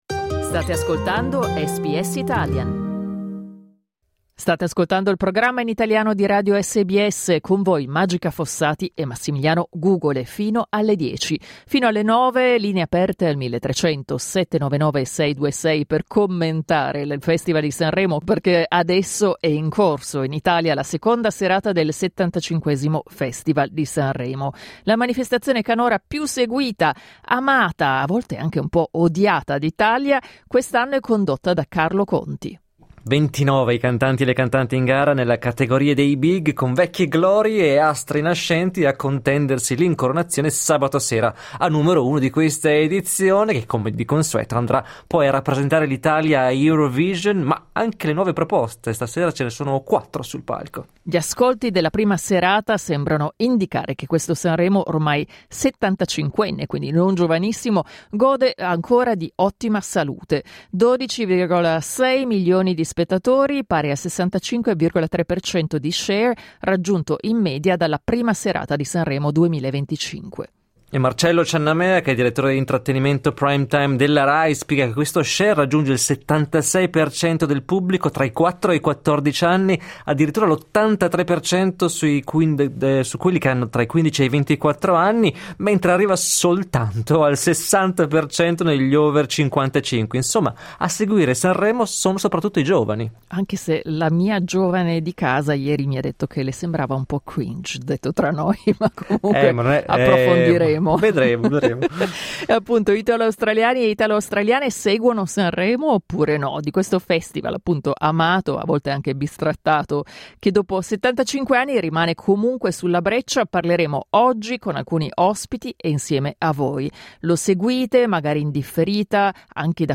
al telefono dall'Italia